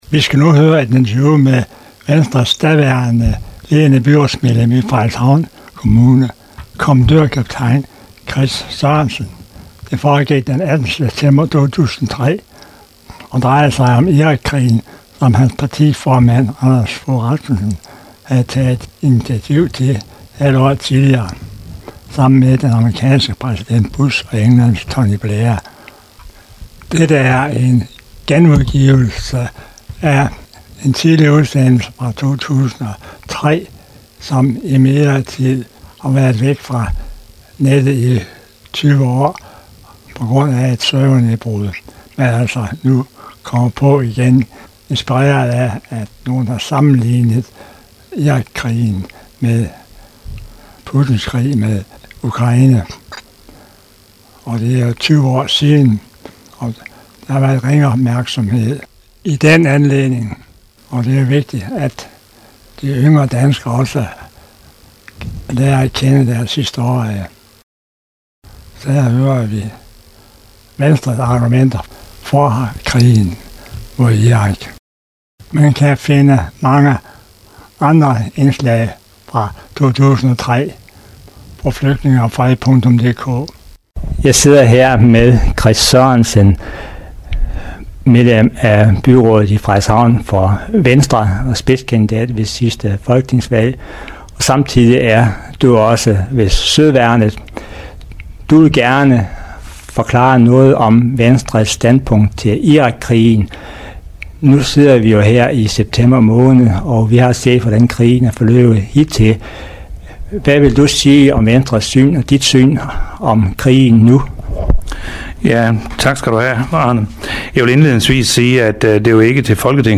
Irakkrigen, interview med kommandørkaptajn og byrådsmedlem for V i Frederikshavn, Chris Sørensen – Flygtninge og Fred
Interview med Venstres daværende ledende byrådsmedlem i Frederikshavn kommandørkaptajn Chris Sørensen d. 18. september 2003 om Irakkrigen som hans partiformand Anders Fogh Rasmussen havde taget initiativ til et halvt år tidligere sammen med den amerikanske præsident Bush og Englands Tony Blair